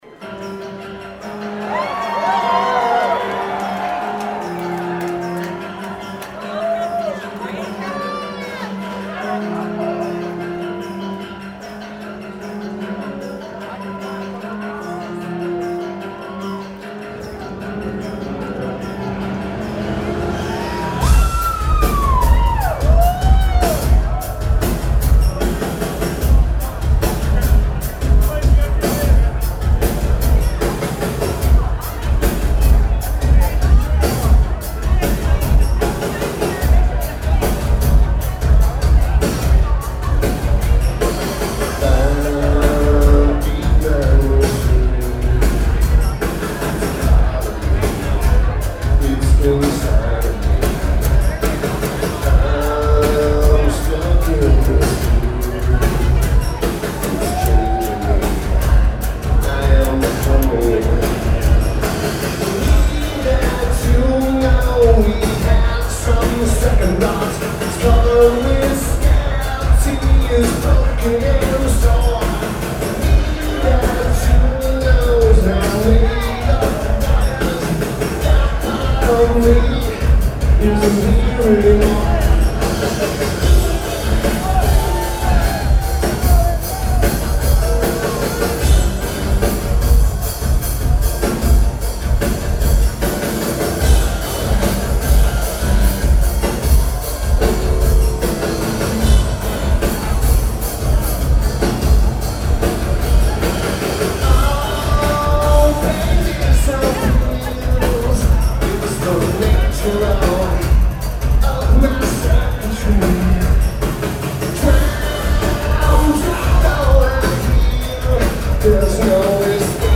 The Palladium
Drums
Guitar
Lineage: Audio - AUD (Roland R05 + Internals)